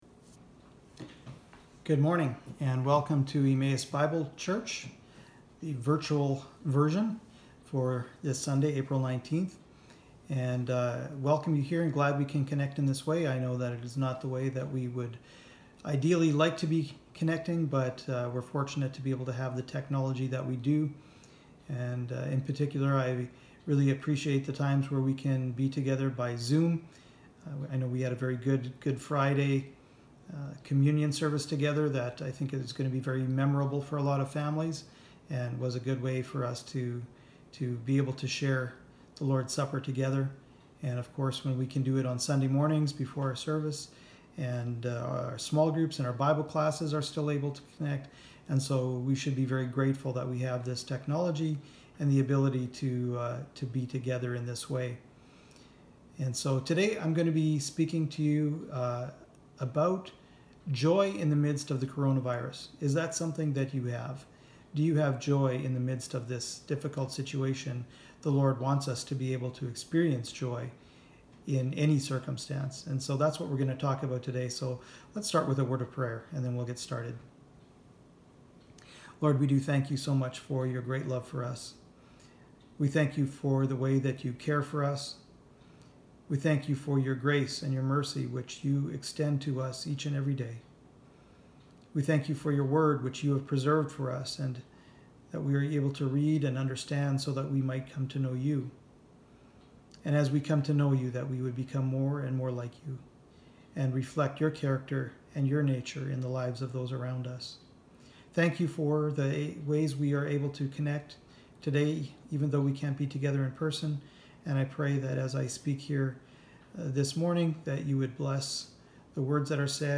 Passage: James 1:1-11 Service Type: Sunday Morning